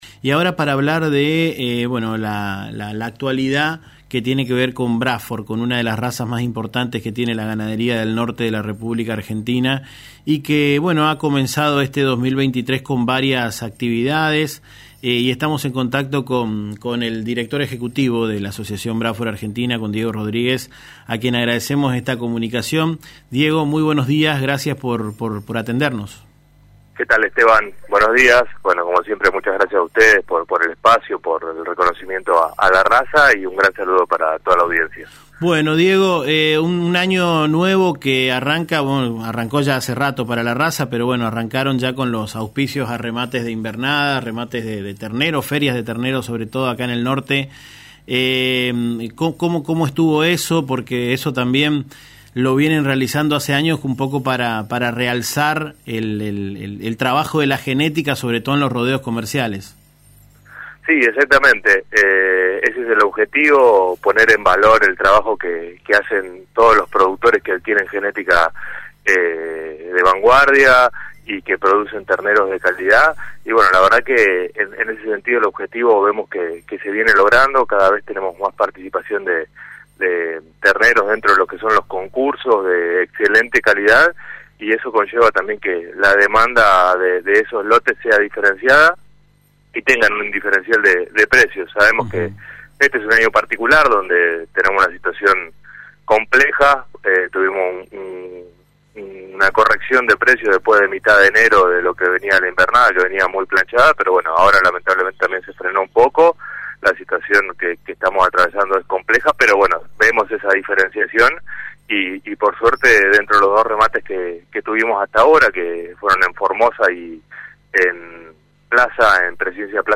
En diálogo con Aires de Campo, programa que se emite por Radio LT 7